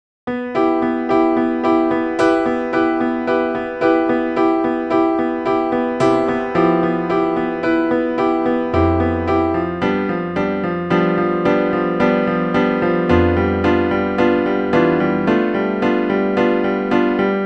Piano Transcription
On transcribing a piano audio file (as added below), a pitch-against-time visualization was created.
Piano.wav